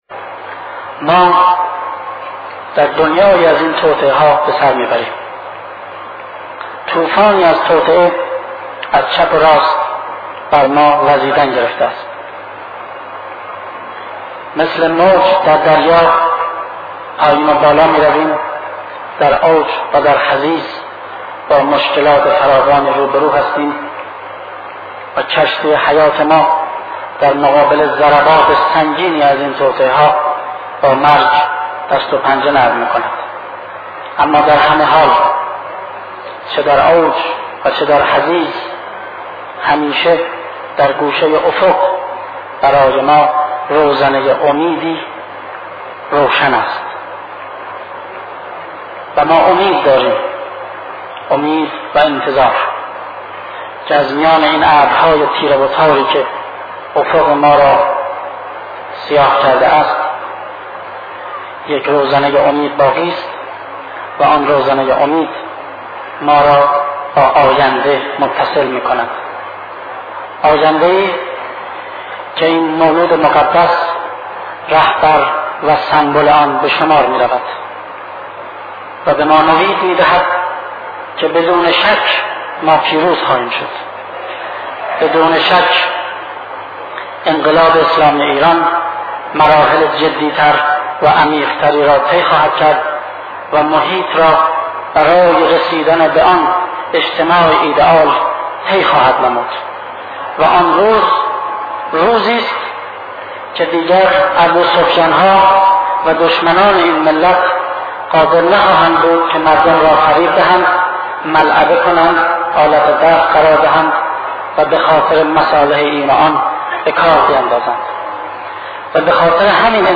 سخنرانی ماندگار شهید مصطفی چمران در خصوص فرج حضرت مهدی (عج) و حکومت جهانی ایشان؛ ضمن بالارفتن آگاهی و رشد اکثریت مردم در آن زمان